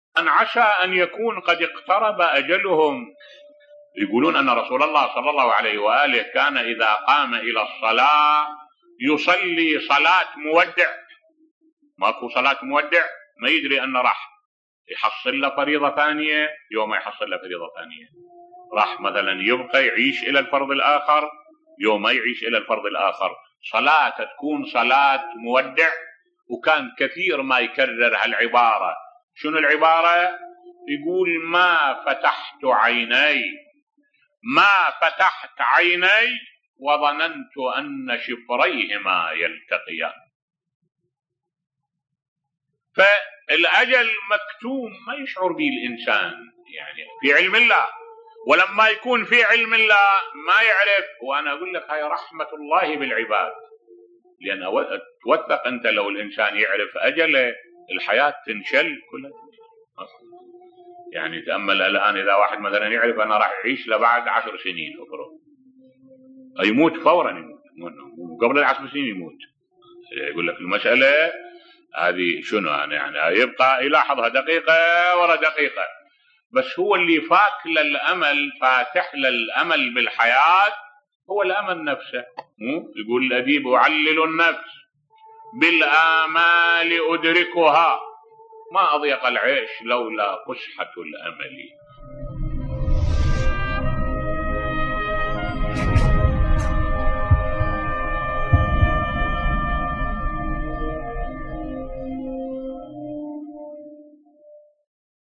ملف صوتی كان رسول الله يصلي صلاة مودع بصوت الشيخ الدكتور أحمد الوائلي